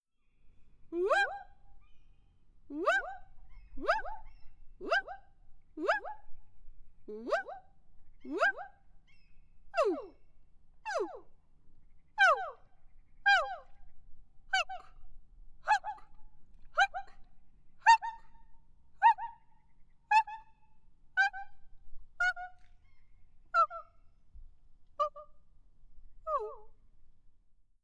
Kiekaisuja Lemin Haukkasaaren kalliomaalauksen edustalla, 29 metrin päässä kalliosta.